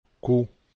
Their sound is the same regardless of the vowel accompanying: